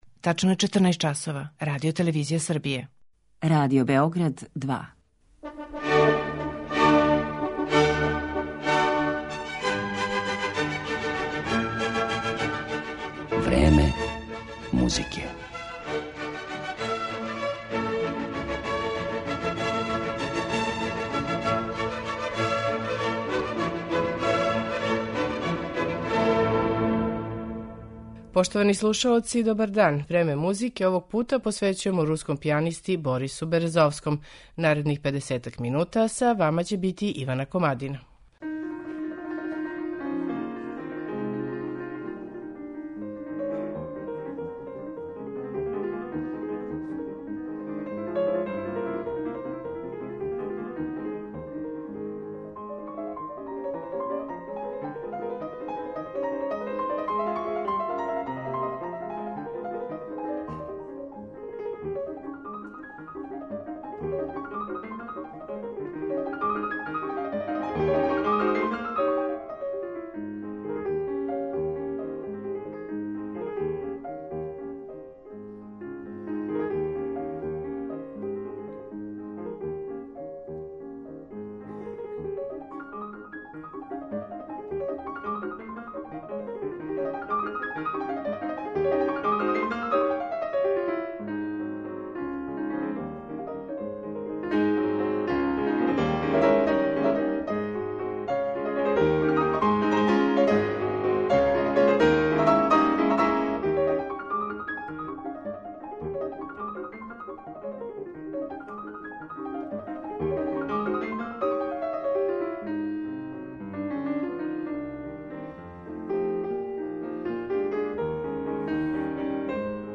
Интерпретације Бориса Березовског
Пијаниста Борис Березовски по неким детаљима свог понашања ван сцене понекад више наликује каквој звезди поп музике или филма.